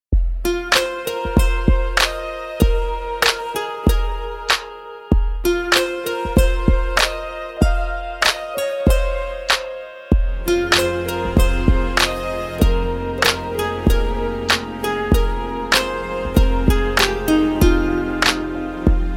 آهنگ موبایل ملایم دارای باس